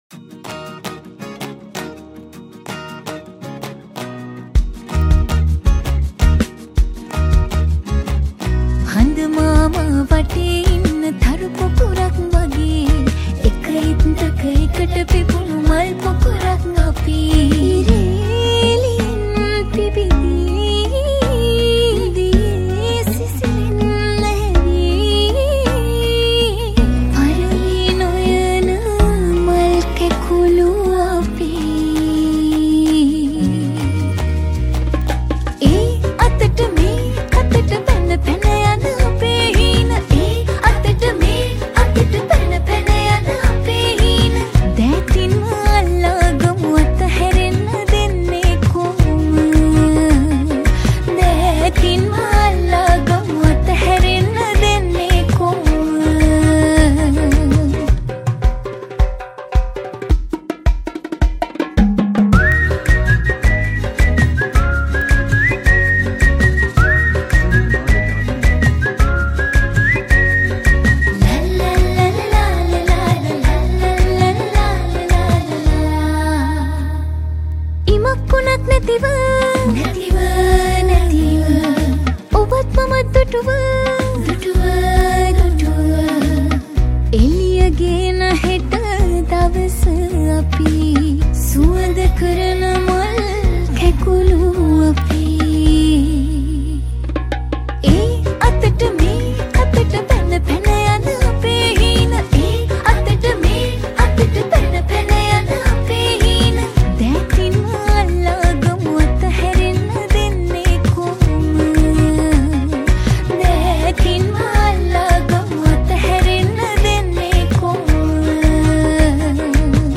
Vocal
Category: Teledrama Song